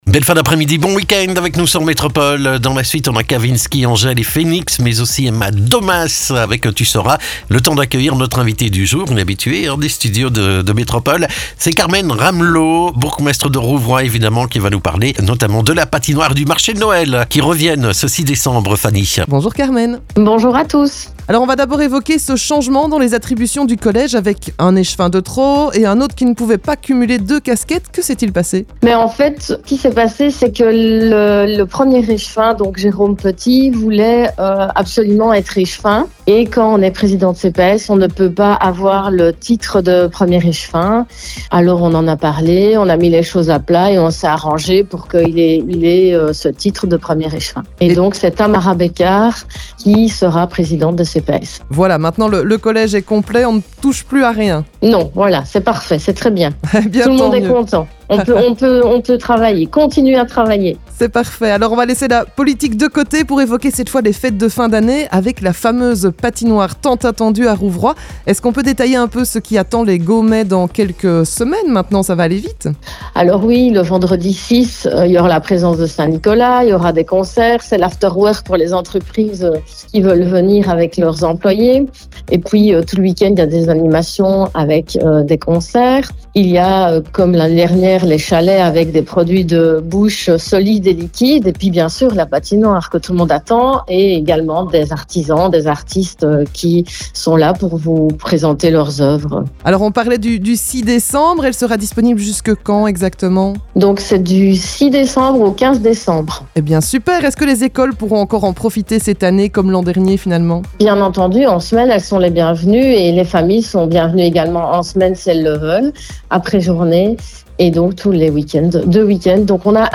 C’est une habituée de nos studios puisqu’ils sont installés sur sa commune au cœur du Rox.
Vous l’aurez compris, nous recevons Carmen Ramlot, bourgmestre de Rouvroy ce vendredi.